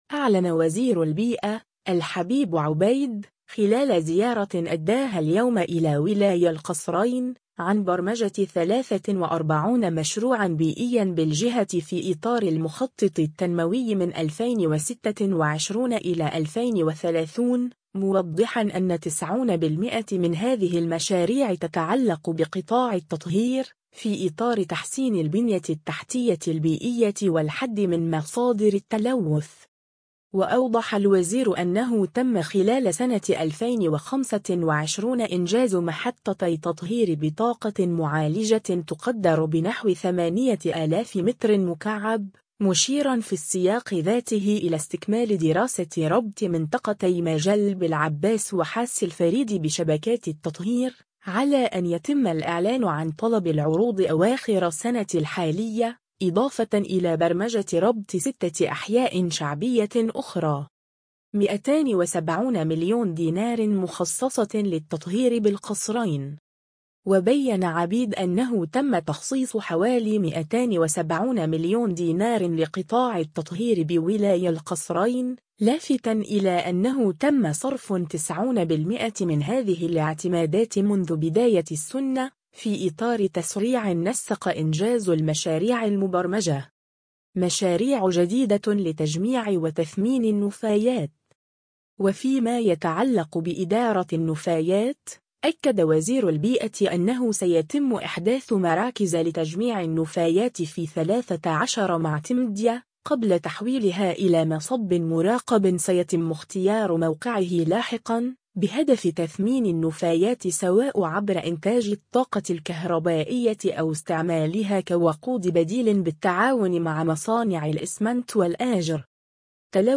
أعلن وزير البيئة، الحبيب عبيد، خلال زيارة أداها اليوم إلى ولاية القصرين، عن برمجة 43 مشروعًا بيئيًا بالجهة في إطار المخطط التنموي 2026-2030، موضحًا أن 90 بالمائة من هذه المشاريع تتعلّق بقطاع التطهير، في إطار تحسين البنية التحتية البيئية والحدّ من مصادر التلوث.